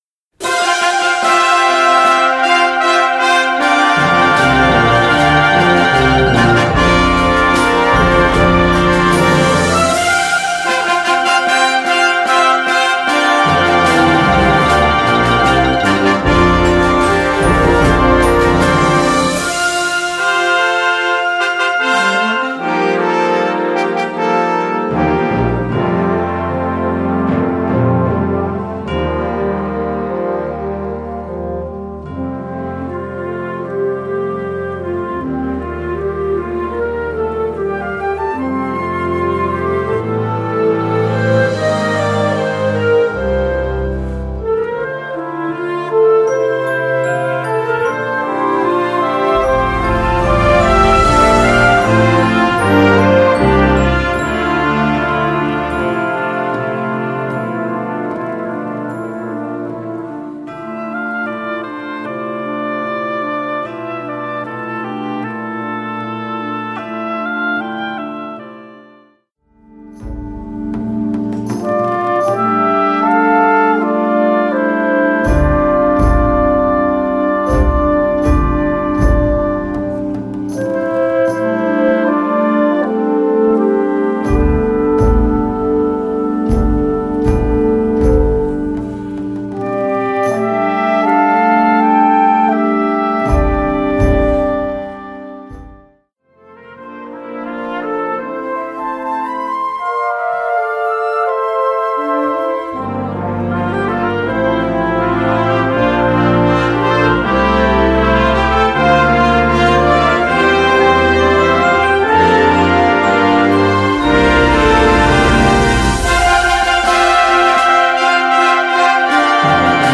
Categorie Harmonie/Fanfare/Brass-orkest
Subcategorie Concertmuziek
Bezetting Ha (harmonieorkest)